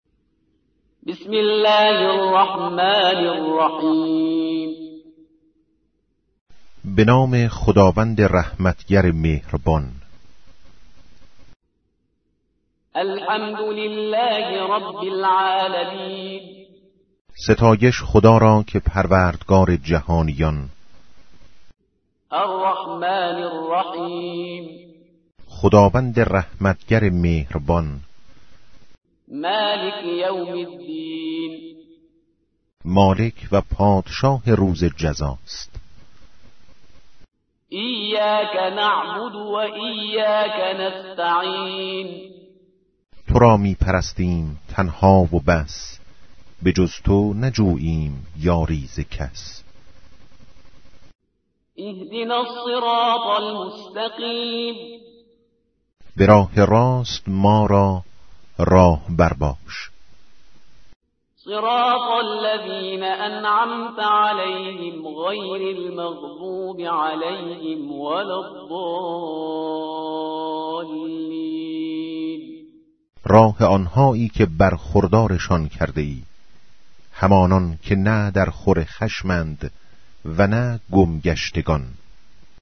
قرآن کریم – el Corán | El noble Corán recitado en Árabe, Español y Persa – قرائت قرآن مجید با ترجمۀ اسپانیایی و فارسی